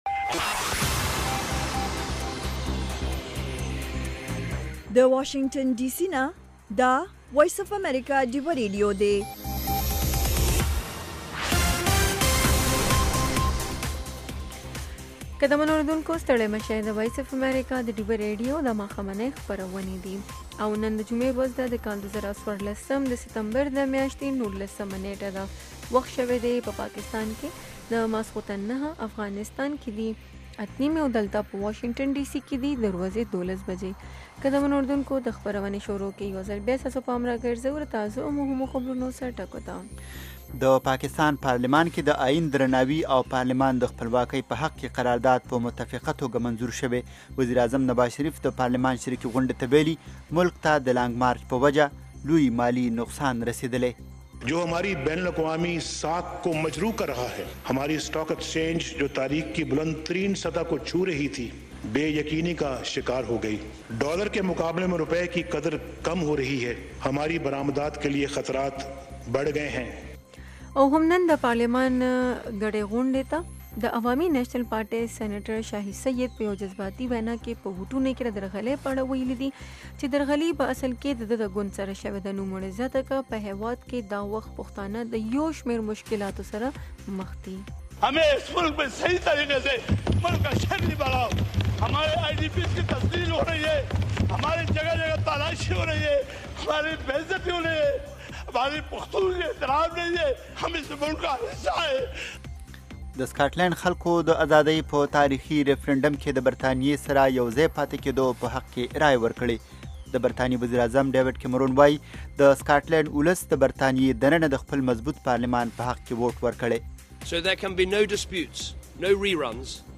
دا یو ساعته خپرونه خونده ورې سندرې لري میلمانه یې اکثره سندرغاړي، لیکوالان، شاعران او هنرمندان وي.